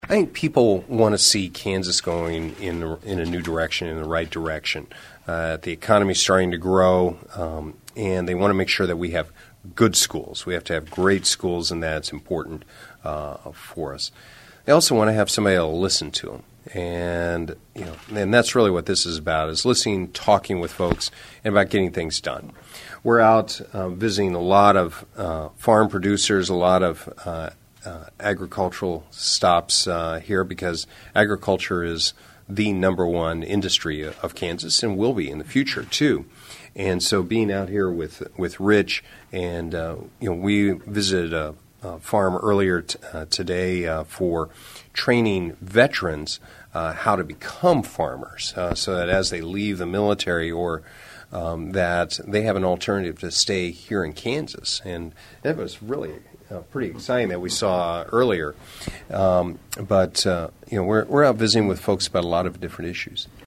Colyer told KSAL News the overwhelming sentiment he is hearing as he travels Kansas is that people want to see the state going in a new direction, in “the right direction”.